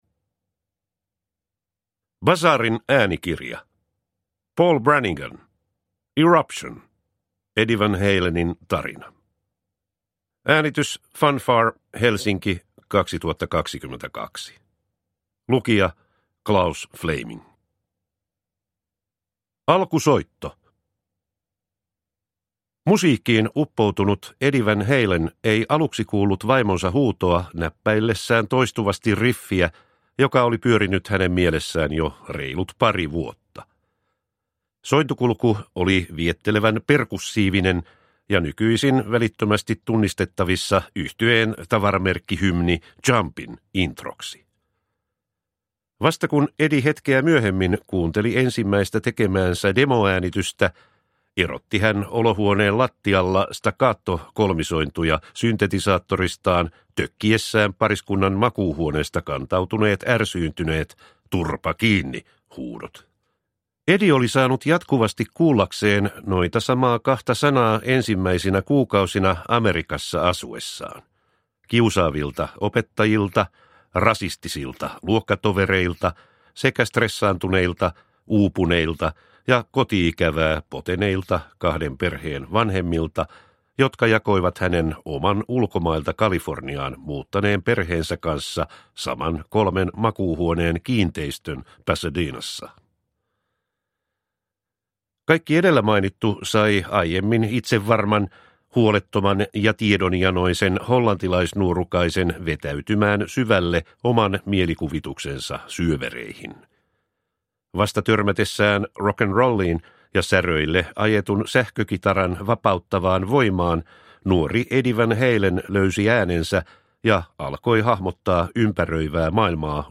Eruption – Eddie van Halenin tarina – Ljudbok – Laddas ner